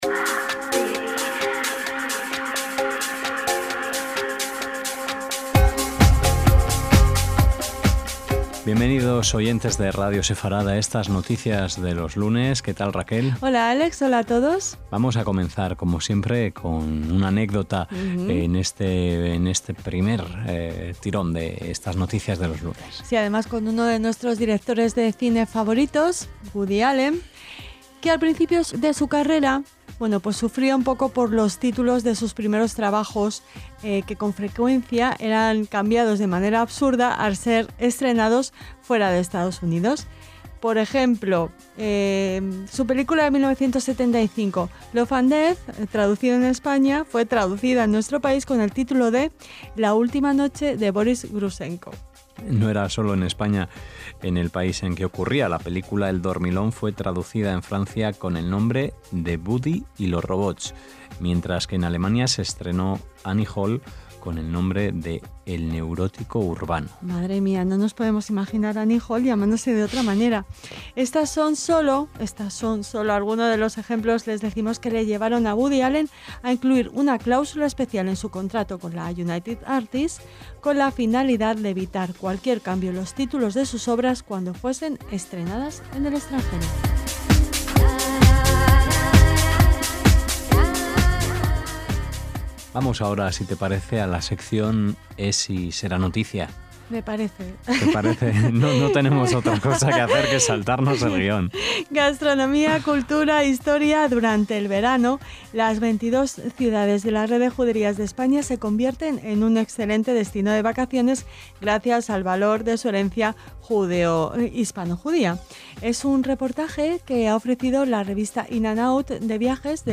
LAS NOTICIAS DE LOS LUNES - Durante el verano, las 22 ciudades de la Red de Juderías de España se convierten en un excelente destino de vacaciones gracias al valor de su herencia hispanojudía. Se lo contamos en este informativo en el que conectamos Ibiza y Tel Aviv... por los pies.